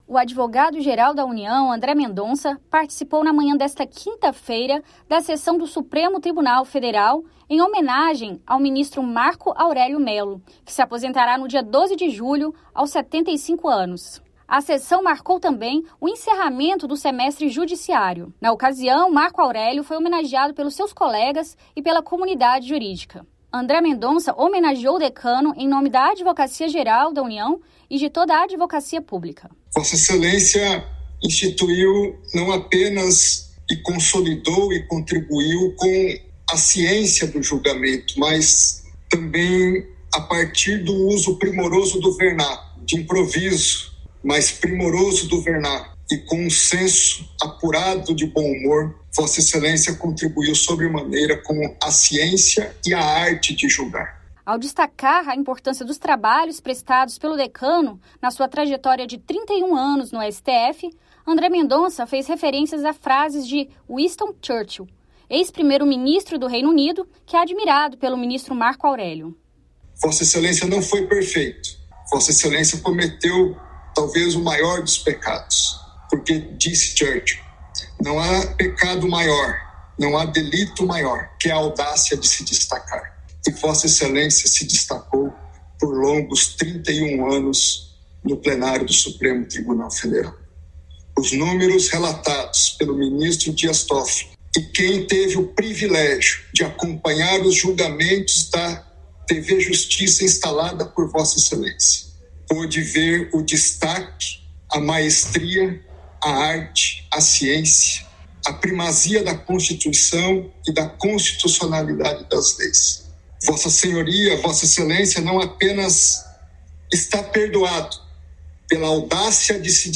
01-07 - André Mendonça presta homenagem ao ministro Marco Aurélio em sessão do STF